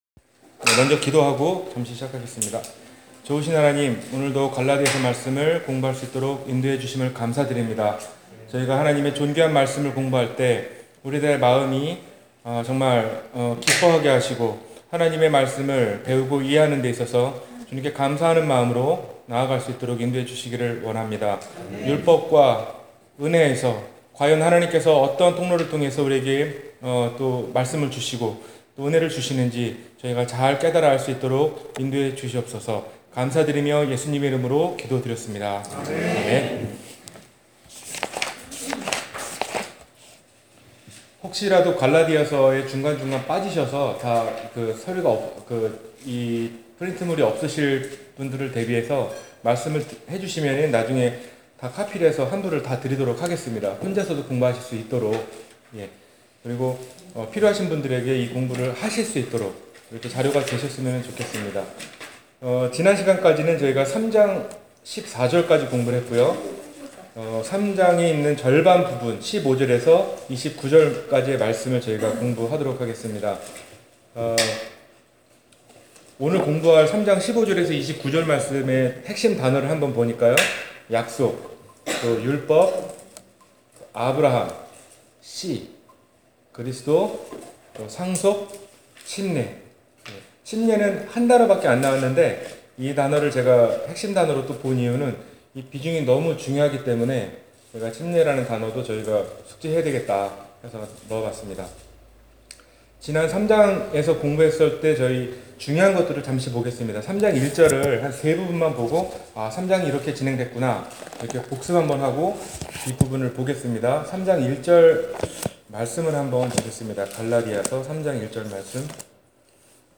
갈라디아서 Passage: 갈라디아서 3장 15-29절 Service Type: 주일성경공부 Bible Text